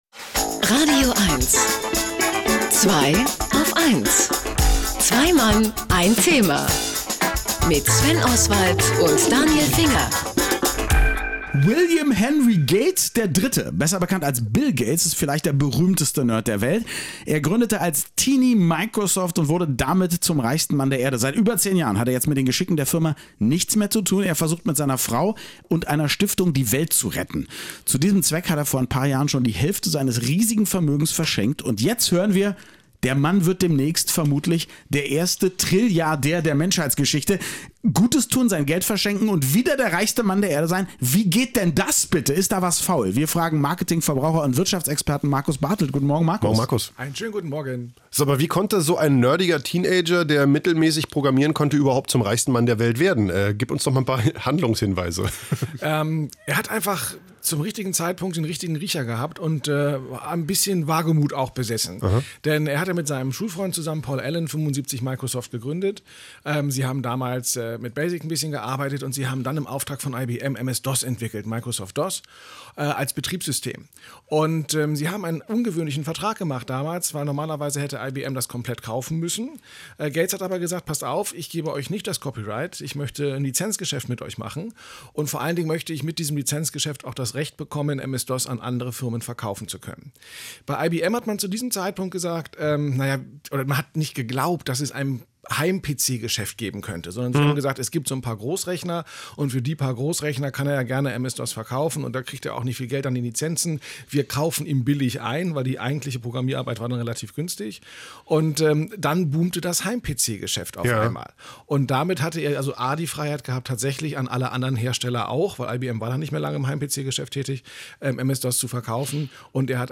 Das Thema der heutigen „Zweiaufeins„-Sendung lautet „Mikro“ und an selbiges habe ich mich ins radioeins-Studio begeben, um ein wenig über Microsoft und insbesondere über Bill Gates zu plaudern: